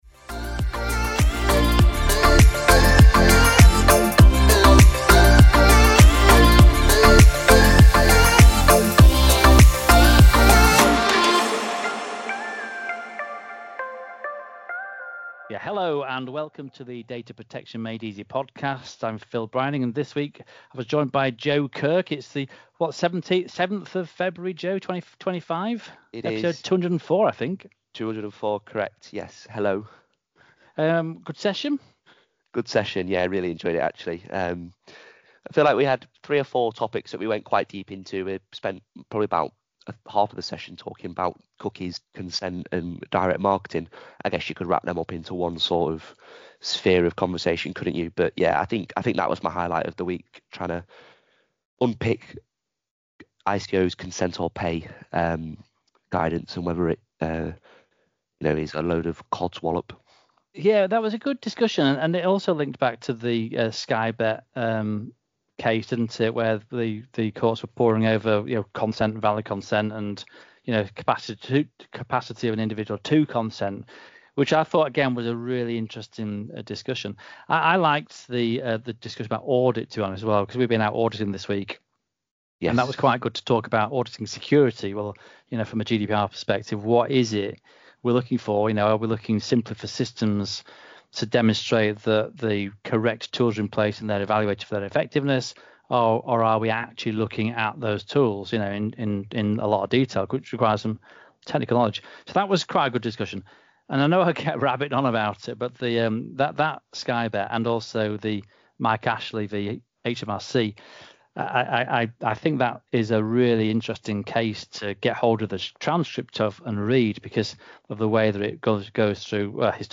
In episode 204 of the Data Protection Made Easy podcast, our experts delve into the latest news, updates, and legislative changes in data protection. Recorded live every Friday with an interactive audience, this fortnightly GDPR Radio session offers insightful discussions and...